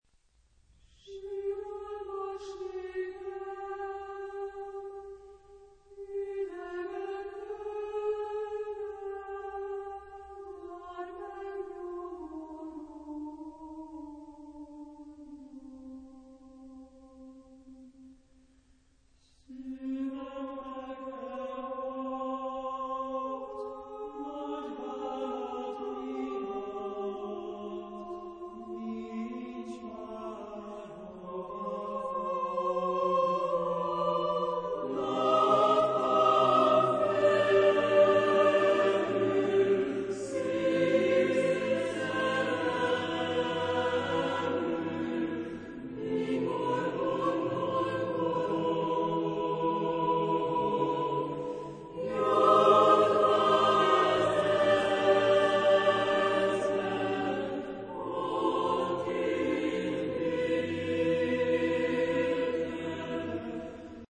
Genre-Style-Forme : Profane
Type de choeur : SATB  (4 voix mixtes )